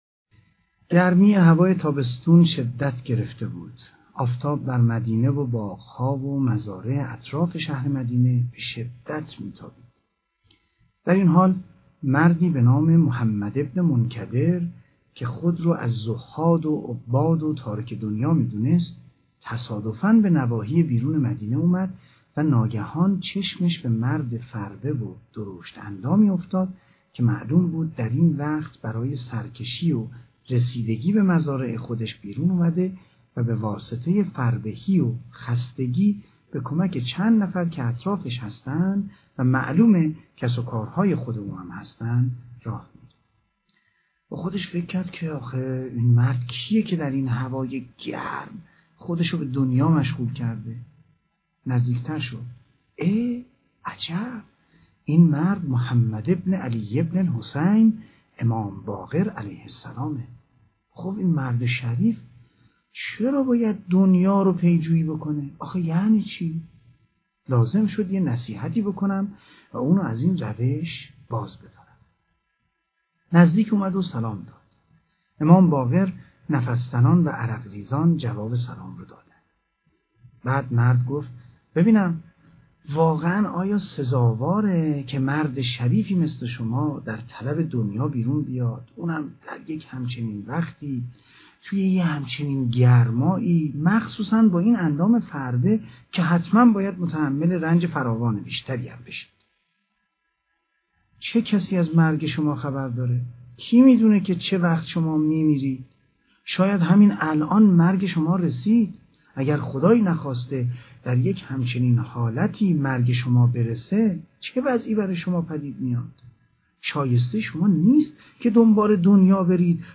دانلود کتاب صوتی داستان راستان - استاد مرتضی مطهری